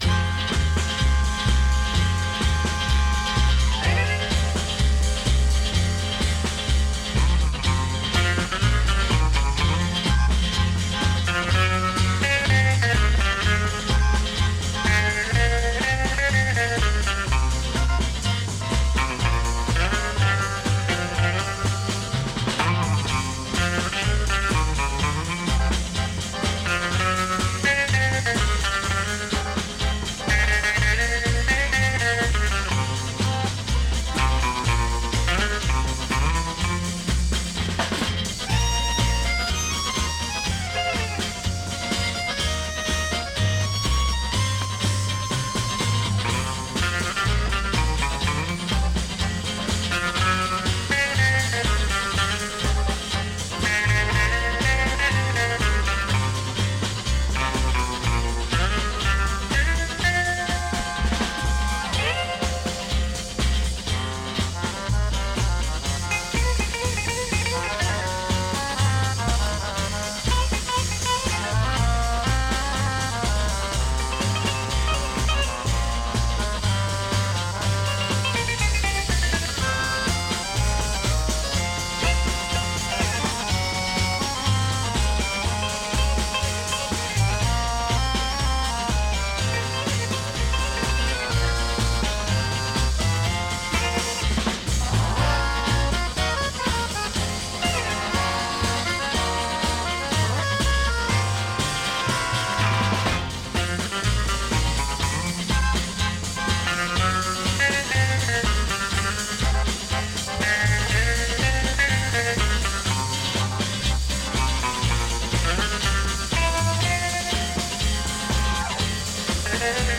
blues guitarists
electric guitars and Hammond organ solos